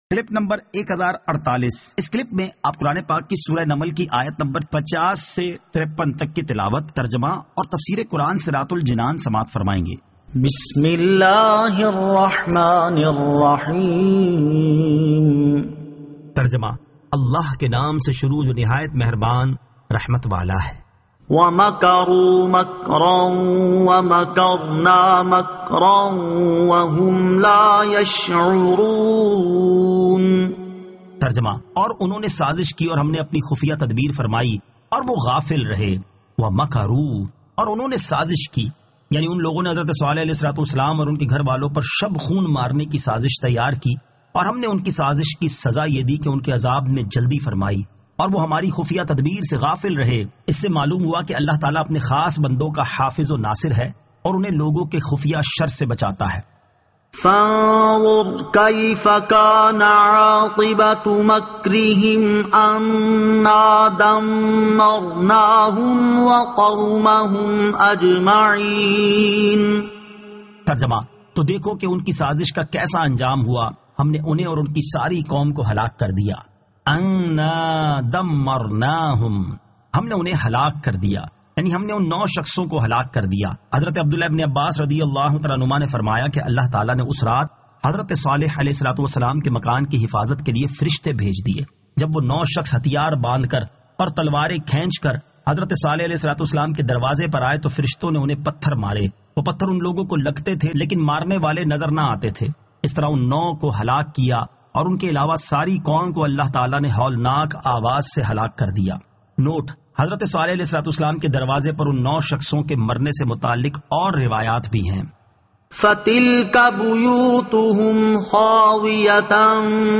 Surah An-Naml 50 To 53 Tilawat , Tarjama , Tafseer